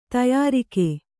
♪ tayārike